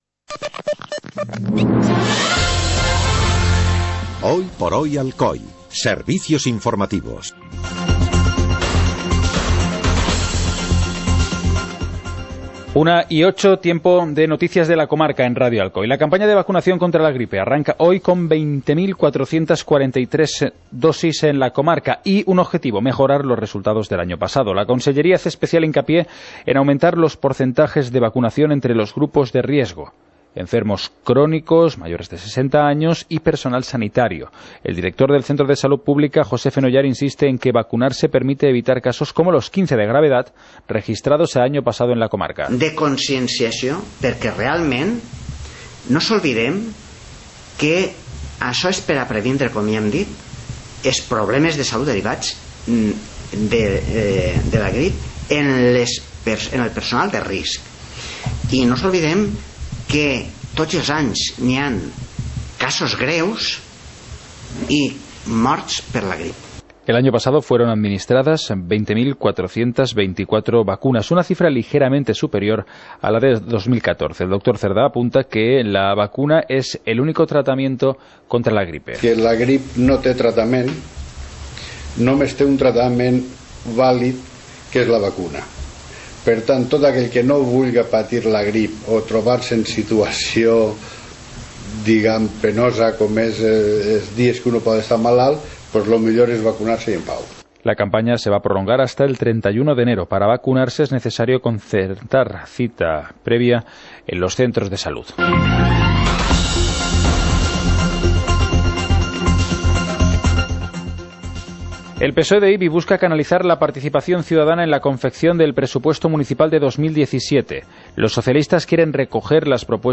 Informativo comarcal - martes, 25 de octubre de 2016